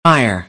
us_phonetics_sound_fire_2023feb.mp3